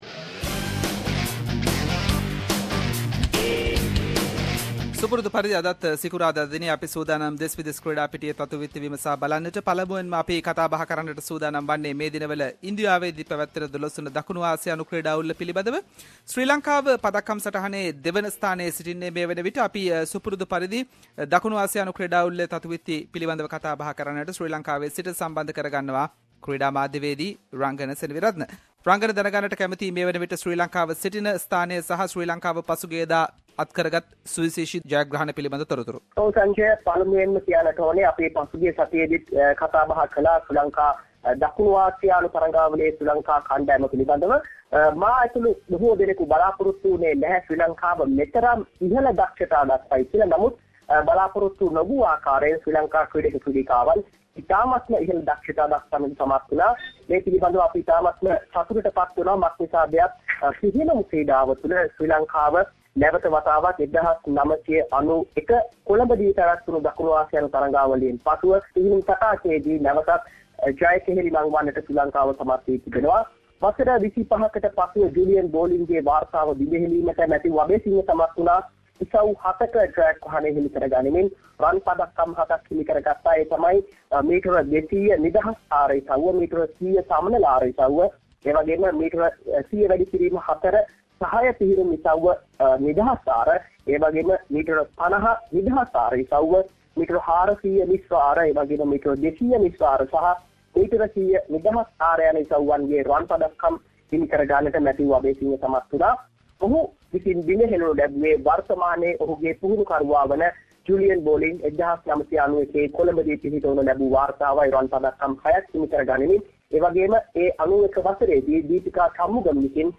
In this weeks SBS Sinhalese sports wrap….Latest from 12th South Asian Games, and Sri Lanka cricket tour to India and many more sports news.